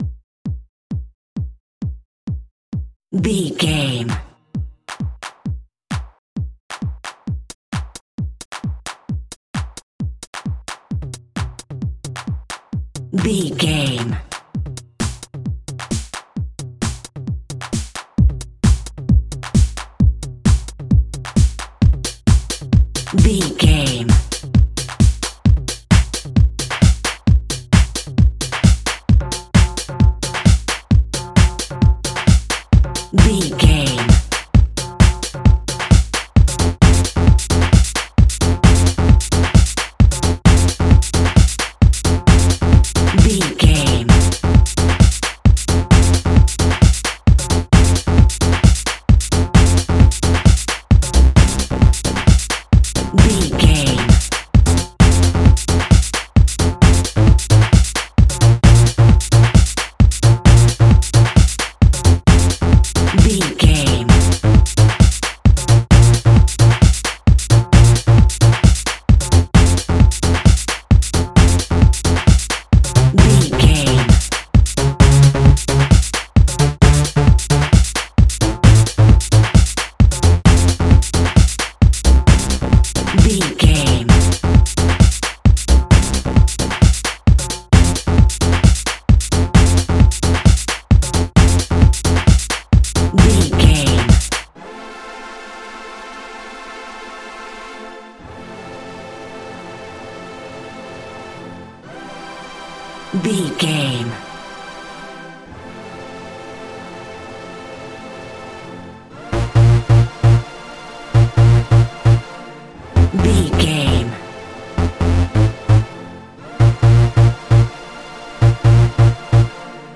Pumping House.
Fast paced
Ionian/Major
Fast
intense
energetic
driving
repetitive
dark
synthesiser
drums
drum machine
electro
house music